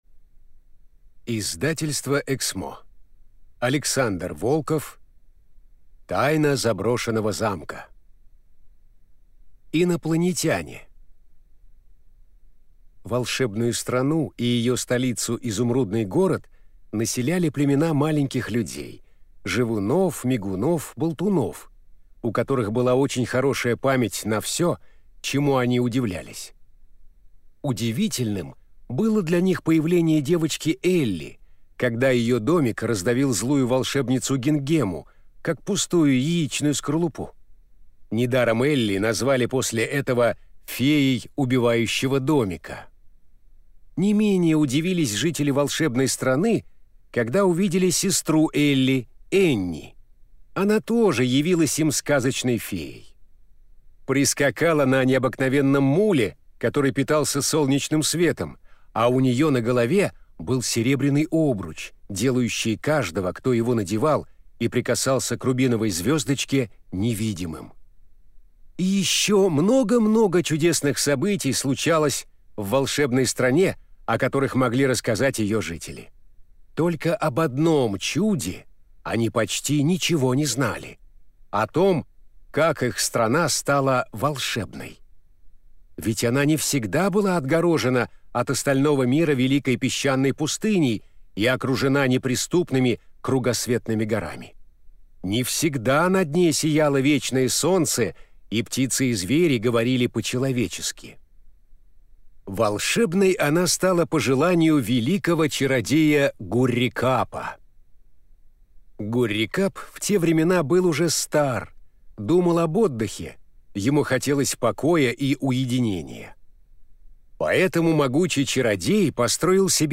Аудиокнига Тайна заброшенного замка | Библиотека аудиокниг